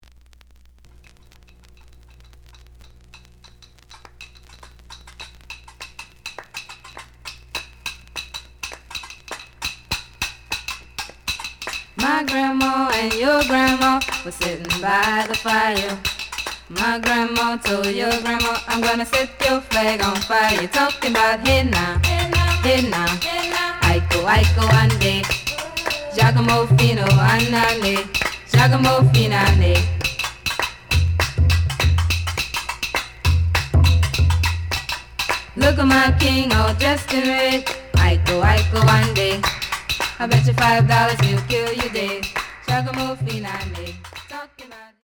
The audio sample is recorded from the actual item.
●Genre: Rock / Pop
Some damage on both side labels. Plays good.)